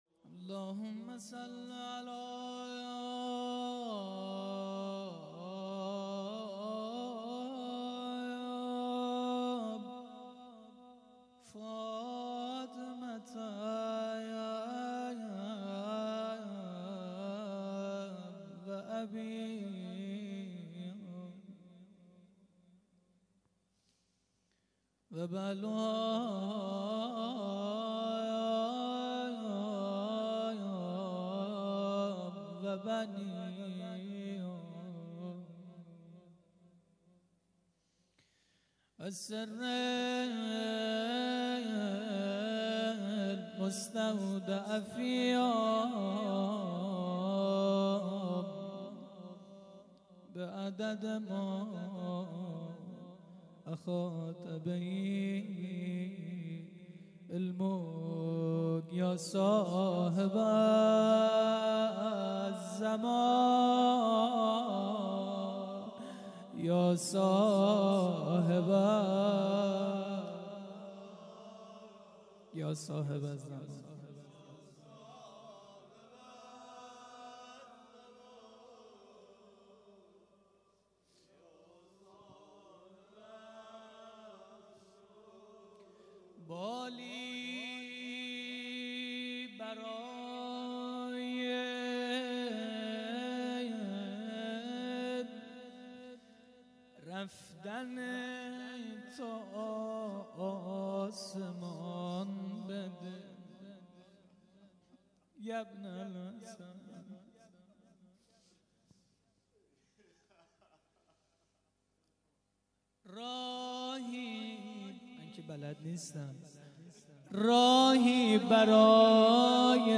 1- روضه امام جواد(ع)